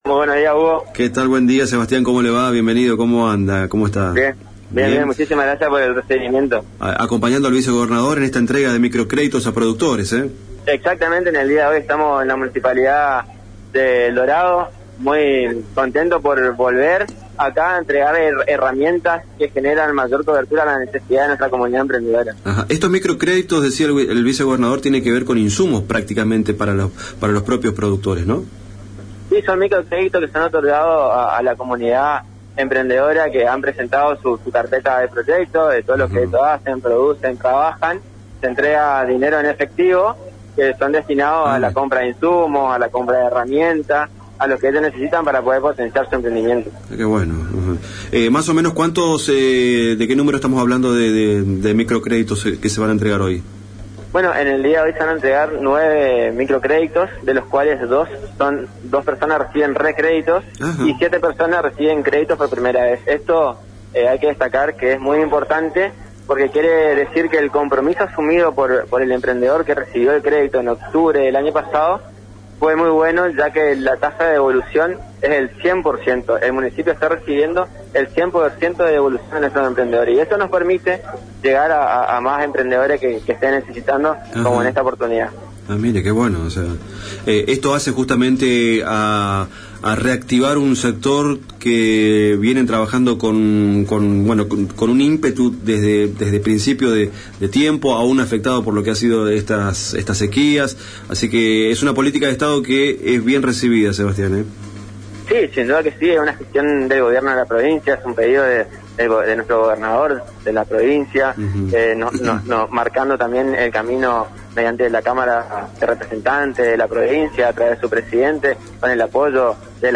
Audio: Sebastián Ortiz, Subsecretario de Coordinación y Promoción de la Economía Social